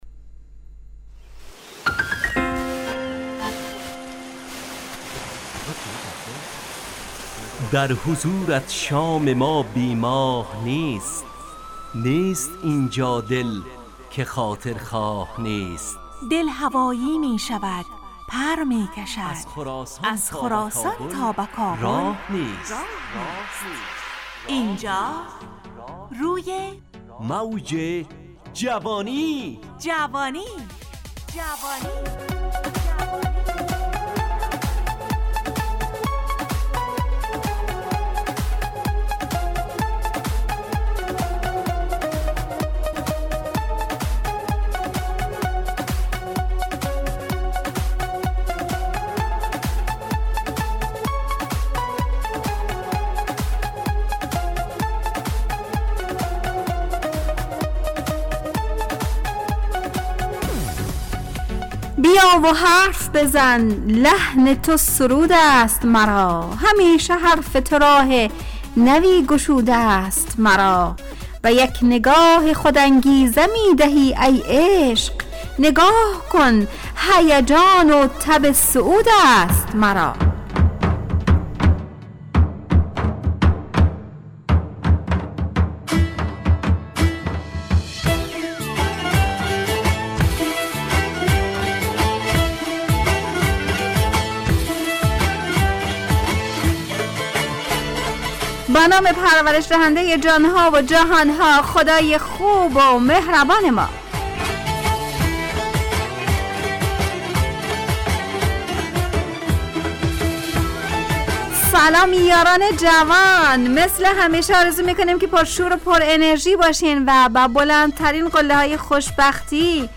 روی موج جوانی، برنامه شادو عصرانه رادیودری.